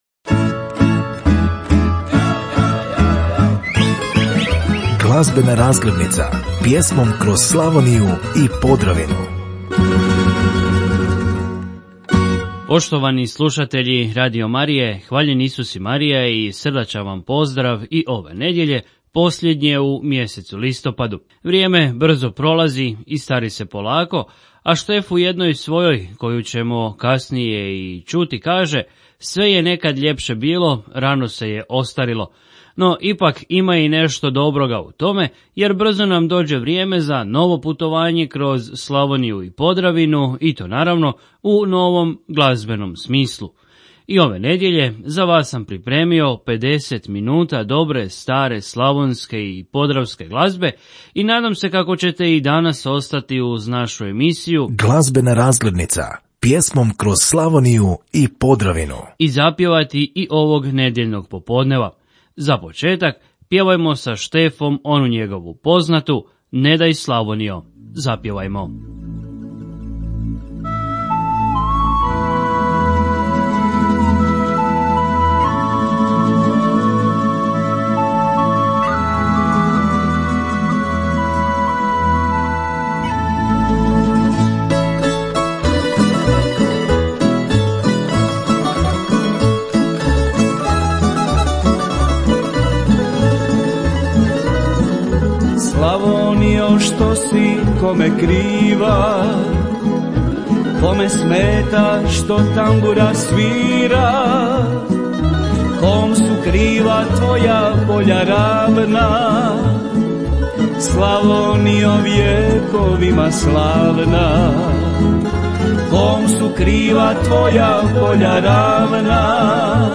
Glazbena razglednica - pjesme Slavonije i Podravine